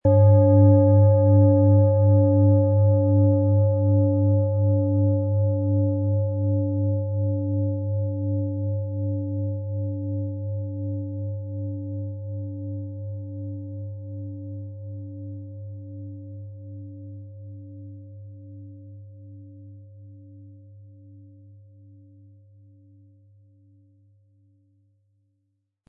Tageston
• Mittlerer Ton: Biorhythmus Körper
Um den Original-Klang genau dieser Schale zu hören, lassen Sie bitte den hinterlegten Sound abspielen.
PlanetentöneTageston & Biorhythmus Körper
MaterialBronze